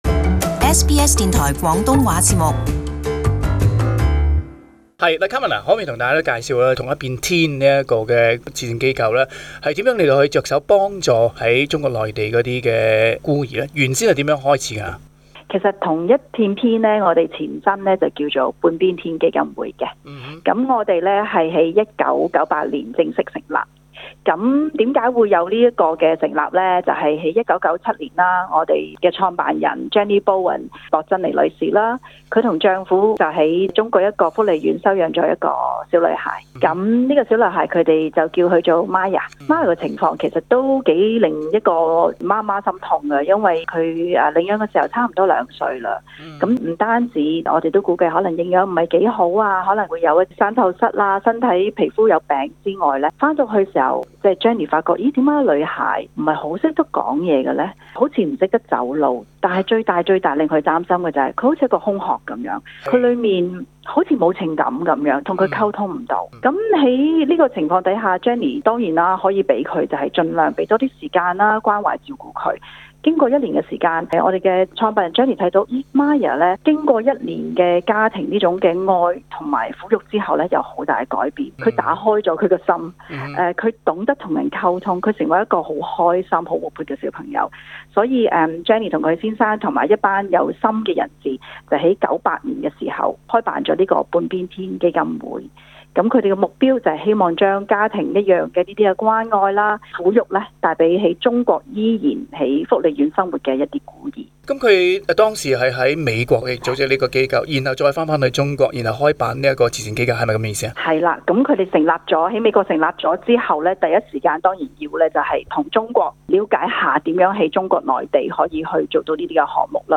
【社區專訪】關愛中國殘障孤兒的愛心行動